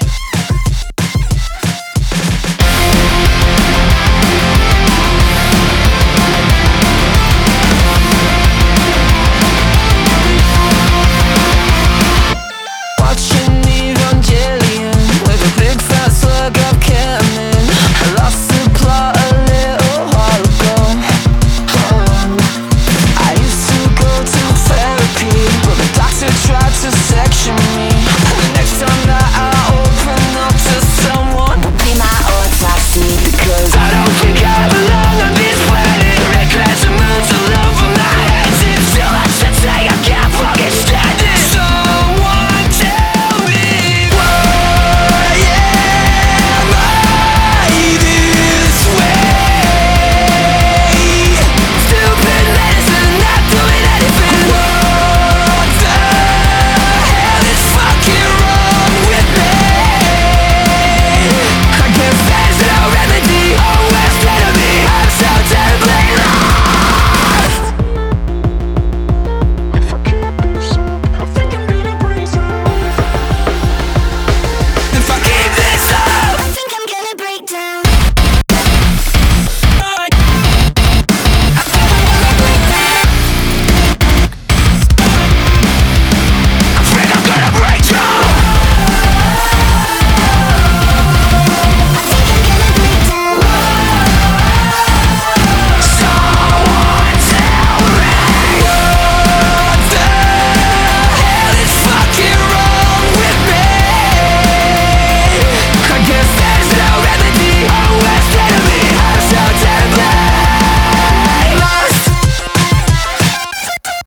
BPM185
Audio QualityPerfect (High Quality)
United Kingdom - Metal/Alternative - #1